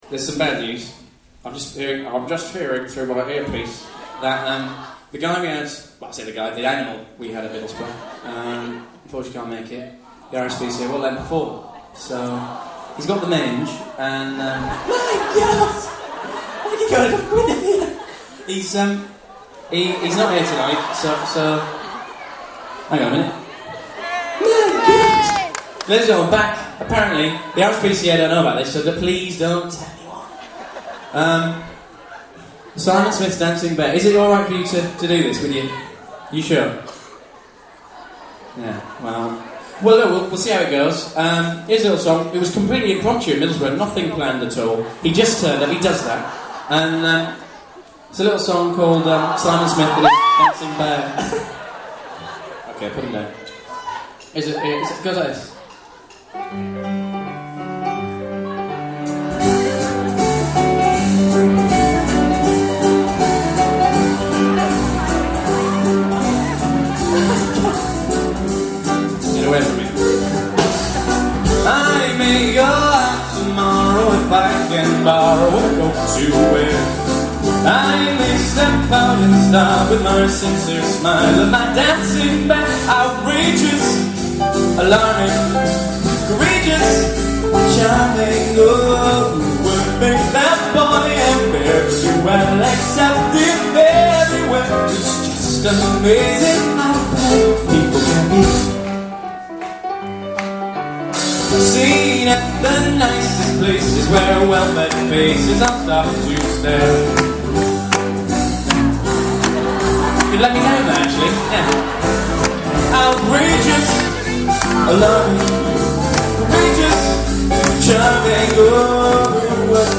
Band Set